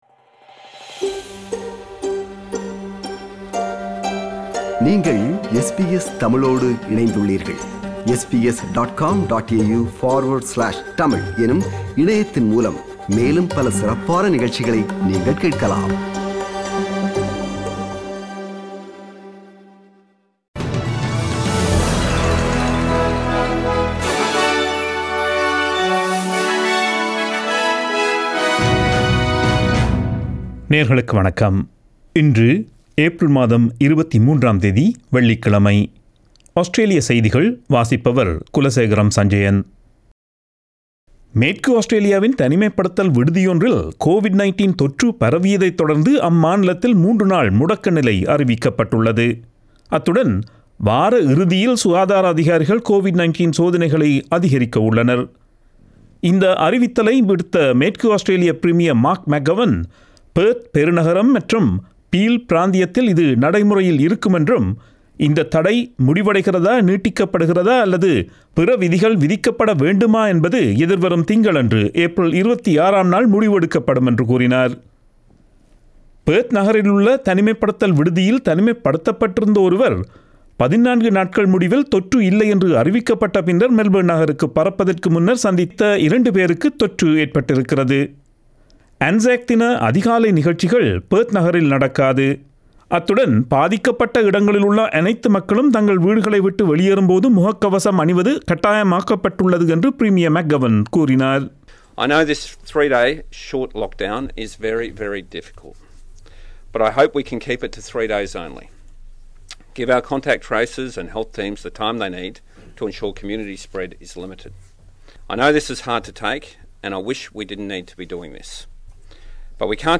Australian news bulletin for Friday 23 April 2021.